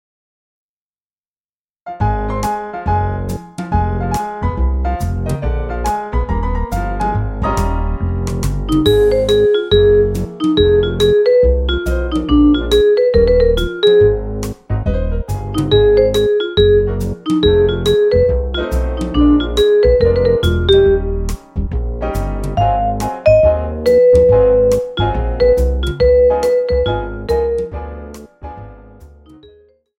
Recueil pour Clarinette